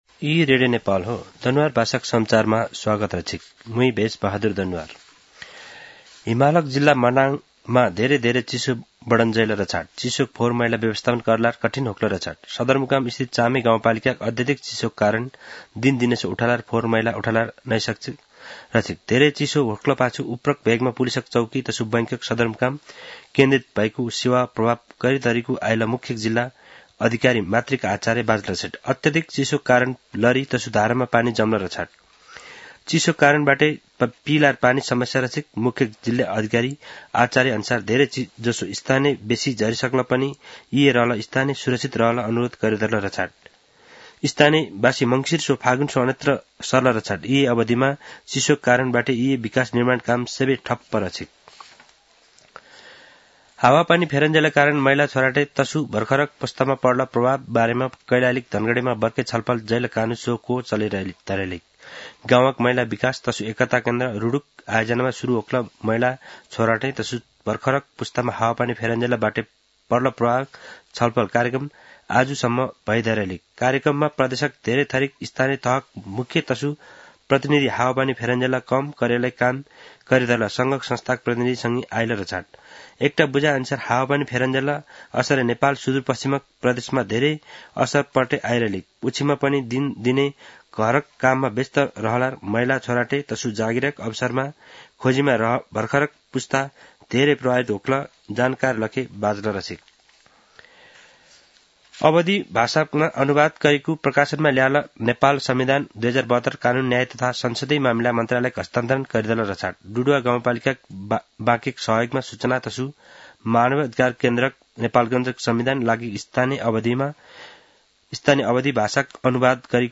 दनुवार भाषामा समाचार : १५ पुष , २०८१